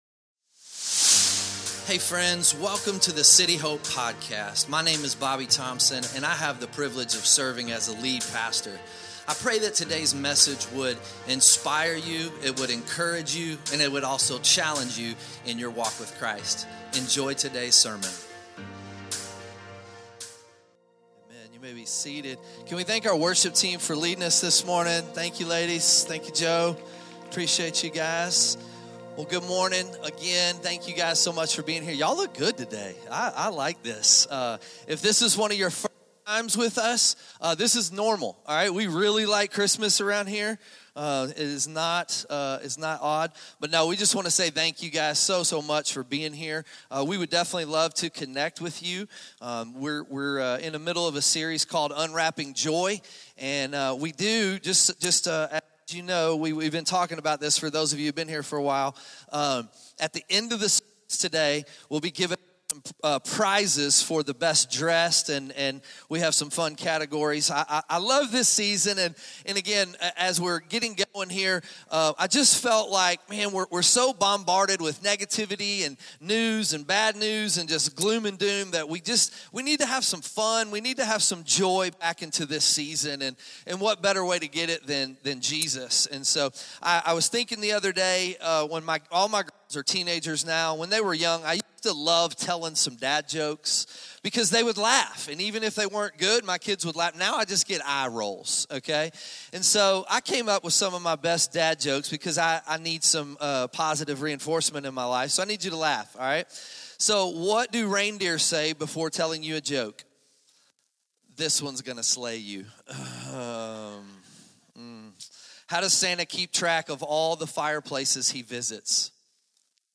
2025 Sunday Morning Where Jesus is